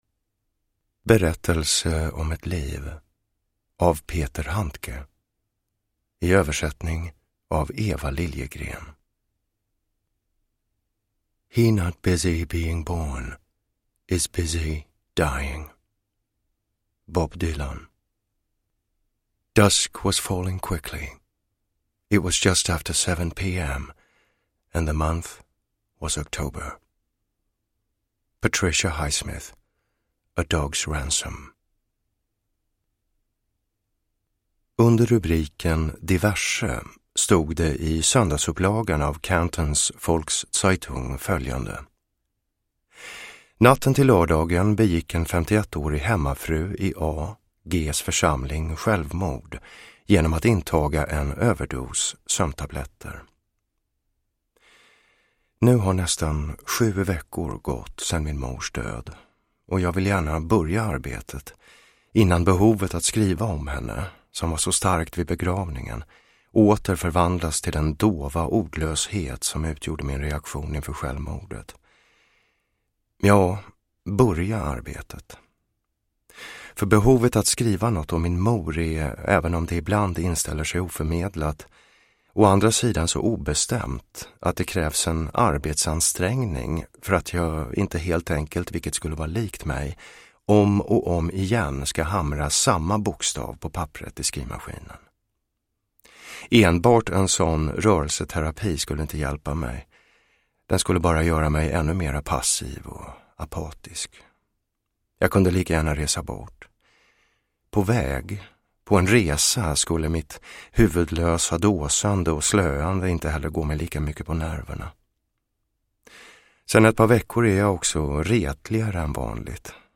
Berättelse om ett liv – Ljudbok – Laddas ner
Uppläsare: Jonas Malmsjö